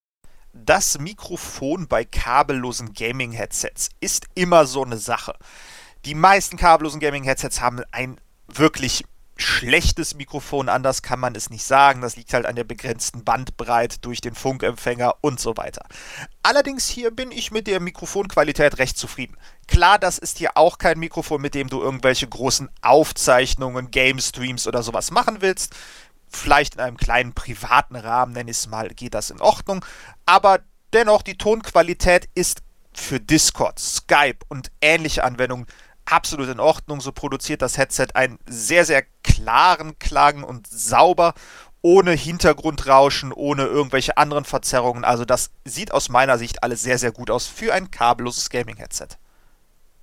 Mikrofonqualität
Das ASUS ROG Pelta bietet ein sehr klares und sauberes Mikrofon. Die Sprachverständlichkeit ist sehr gut.
Mikrofonqualität gut, aber nicht auf professionellem Niveau
Mikrofontest.mp3